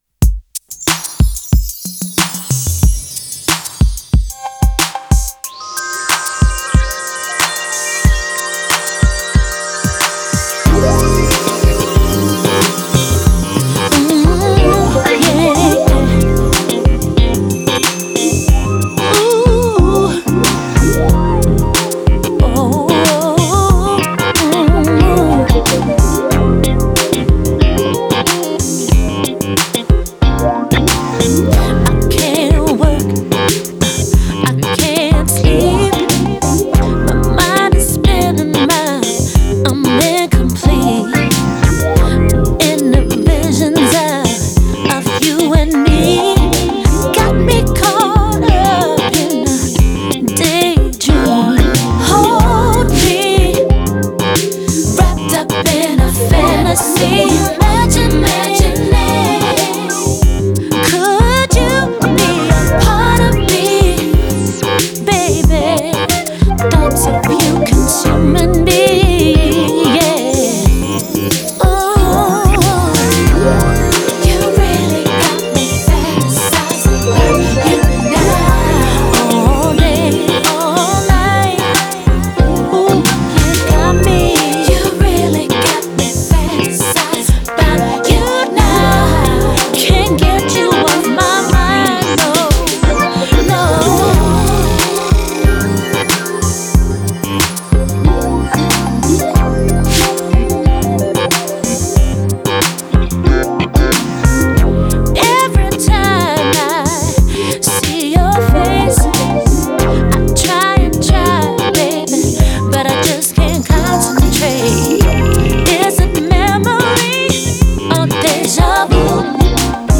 Sydney based duo
with big choruses and sinewy elastic rhythms.